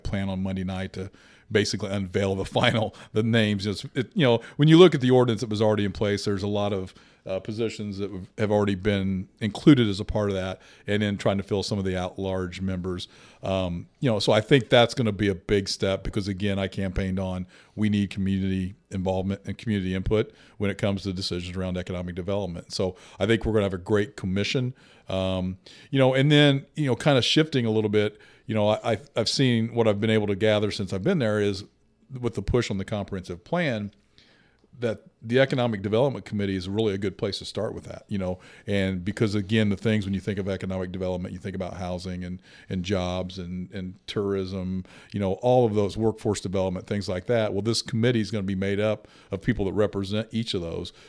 Speaking on our weekly podcast “Talking about the Vandalia,” Mayor Knebel says he’s planning to name the remaining at large member of his Economic Development Committee at tonight’s Vandalia City Council meeting.